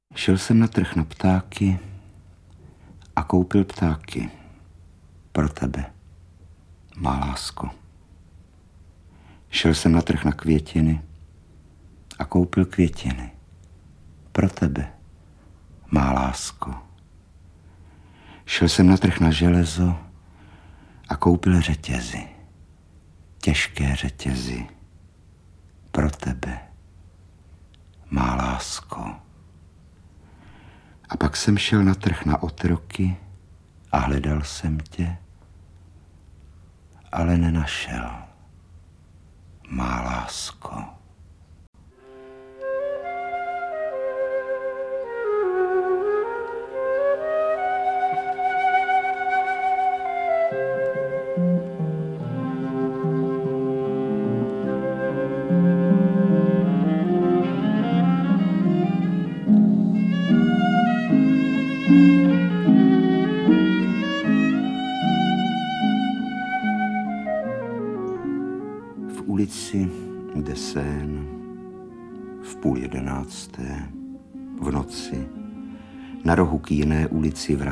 • AudioKniha ke stažení Jako zázrakem, Ze vzpomínek na dětství
Interpret:  Rudolf Hrušínský